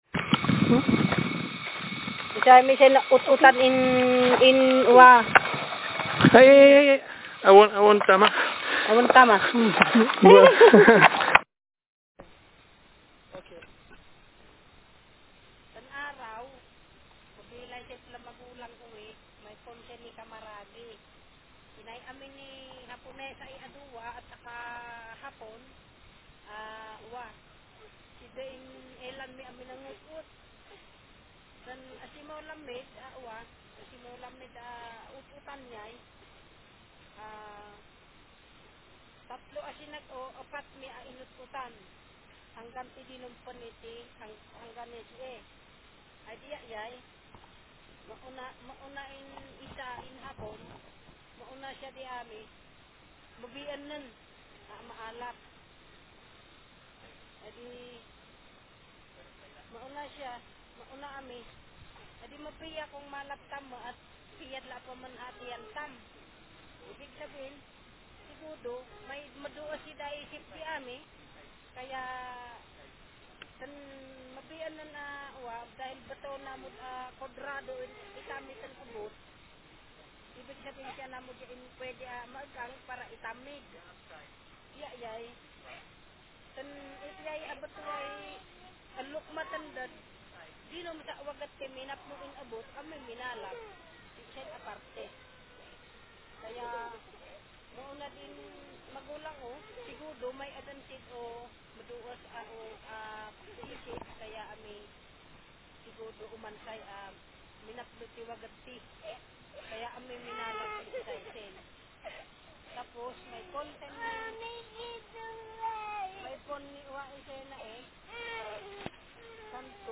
Speaker sex f Text genre personal narrative